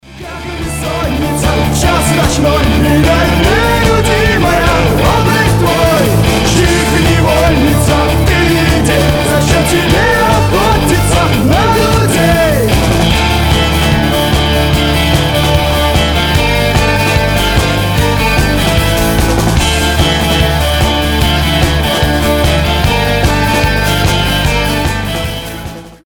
• Качество: 320, Stereo
скрипка
панк-рок
фолк-рок
Symphonic Rock